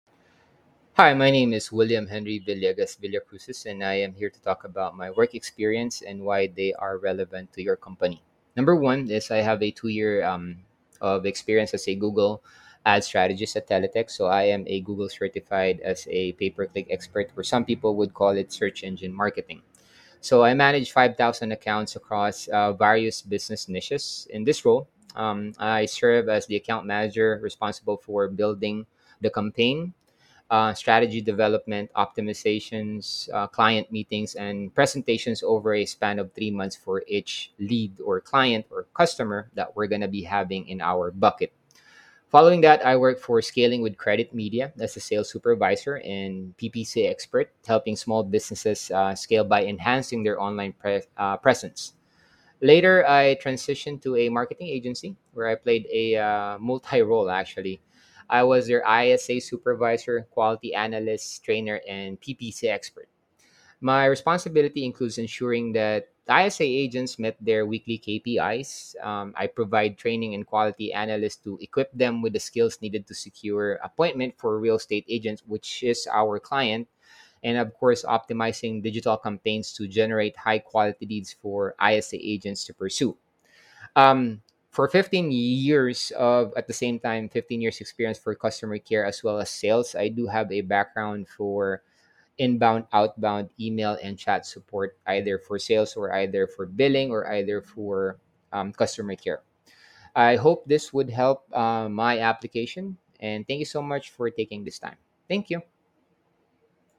Self Introduction
Voice-Introduction-2-2.mp3